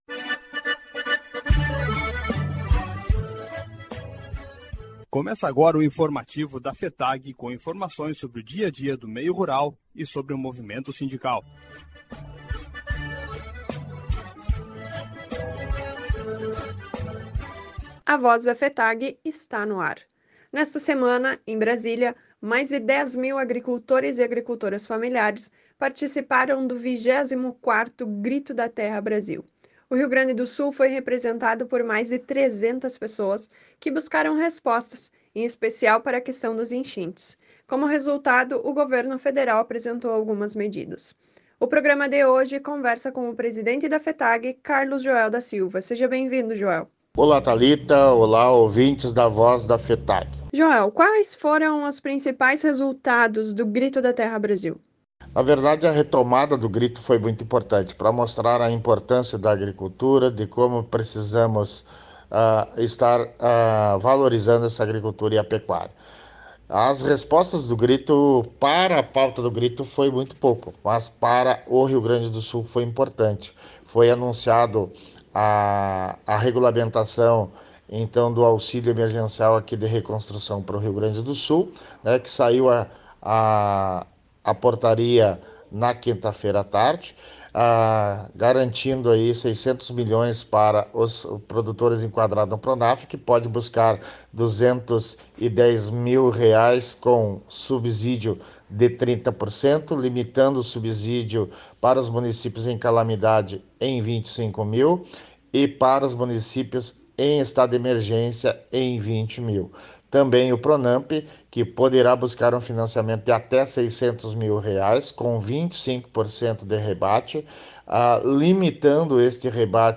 Programa de Rádio A Voz da FETAG-RS